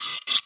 zaba2x.wav